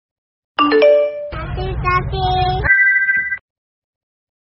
Nada notifikasi WA Cipung ‘Hati-Hati’
Kategori: Nada dering